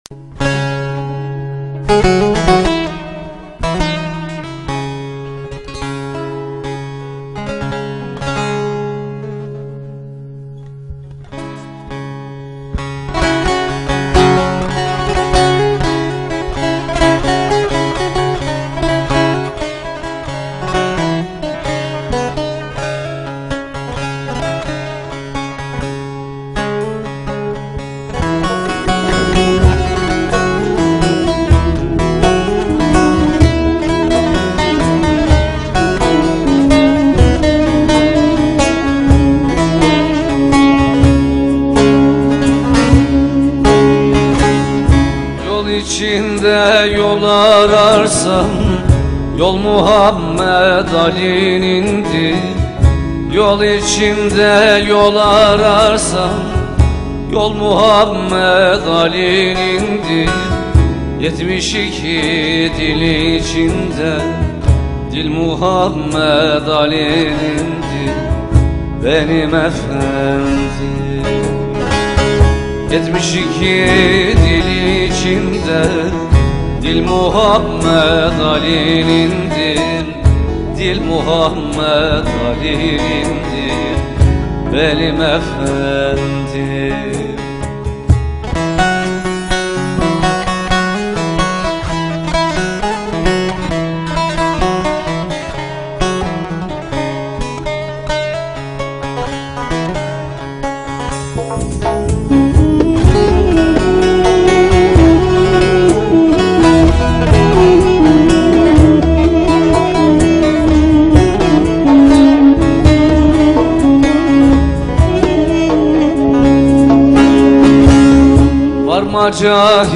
• Nefes, Bektaşi törenlerinde saz eşliğinde söylenir.